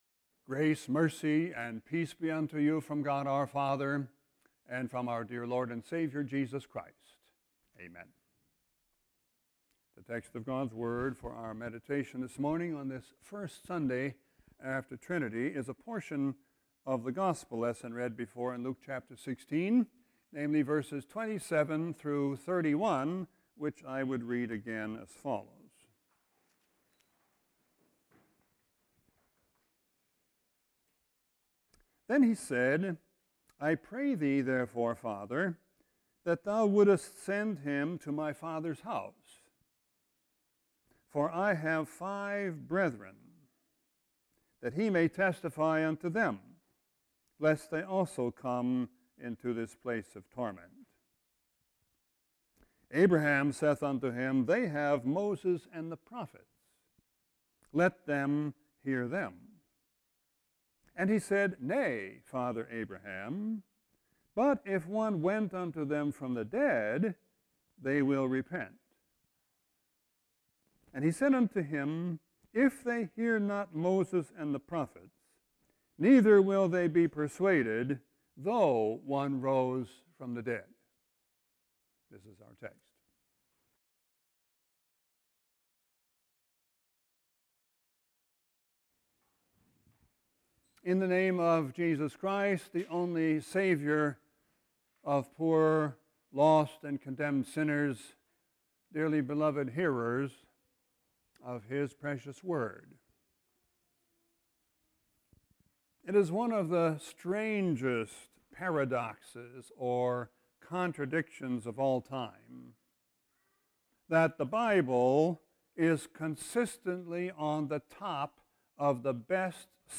Sermon 6-2-13.mp3